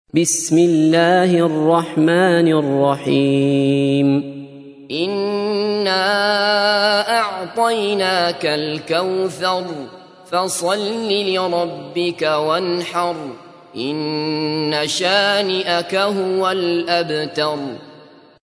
تحميل : 108. سورة الكوثر / القارئ عبد الله بصفر / القرآن الكريم / موقع يا حسين